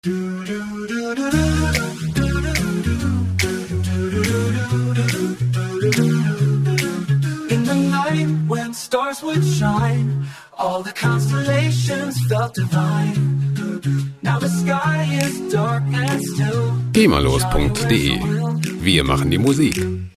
Gema-freie a-cappella Musik
Musikstil: Pop
Tempo: 142 bpm